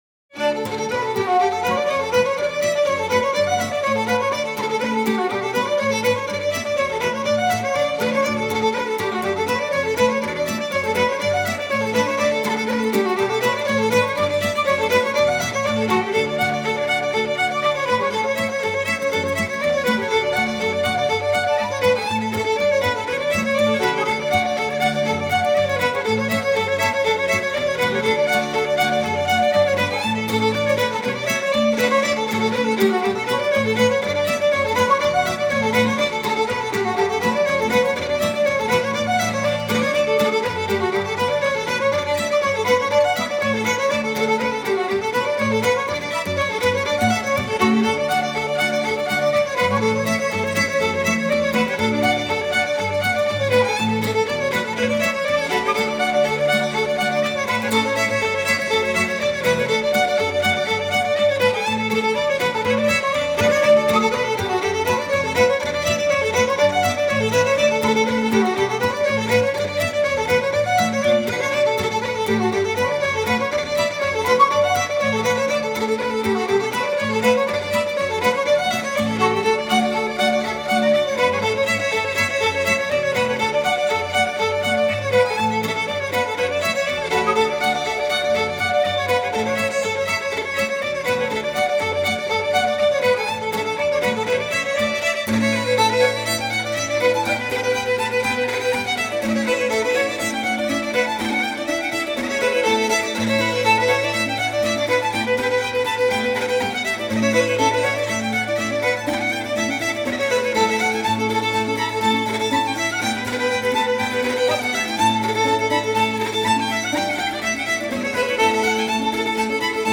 幸好，这是一张现场录音的演奏会唱片。